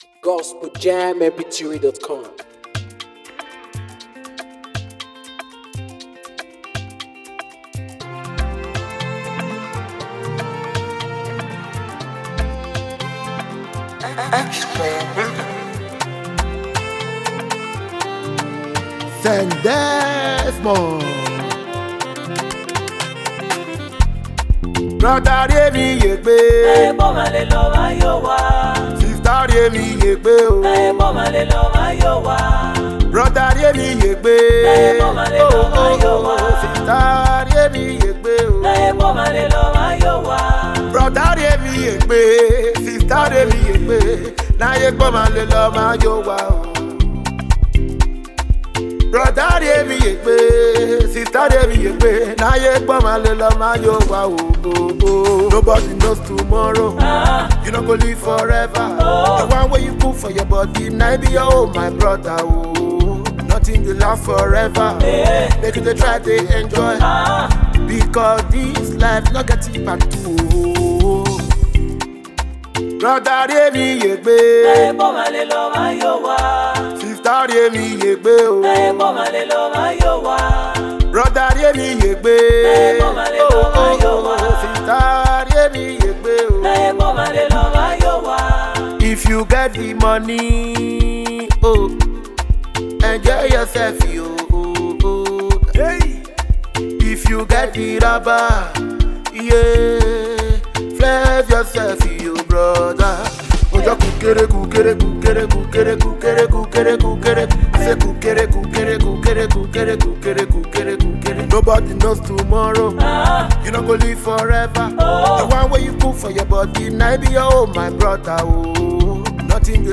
Italian base Gospel singer